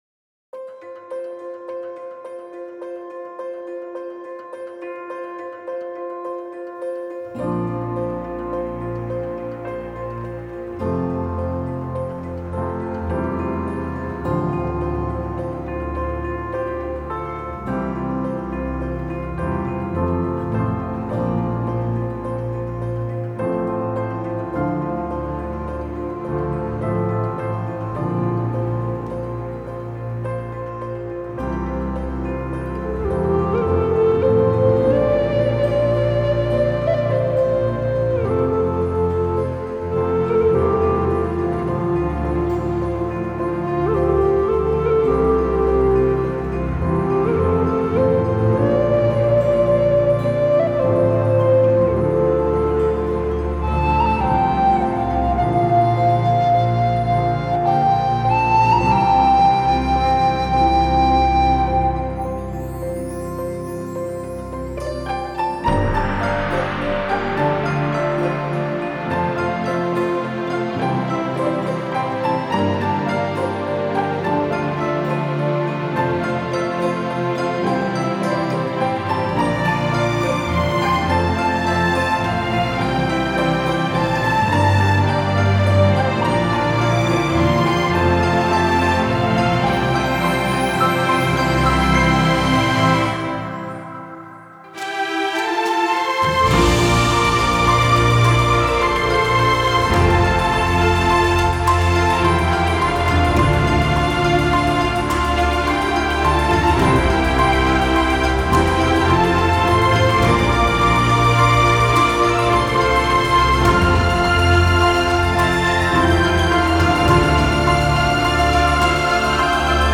史诗气势音乐